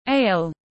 Bia tươi tiếng anh gọi là ale, phiên âm tiếng anh đọc là /eɪl/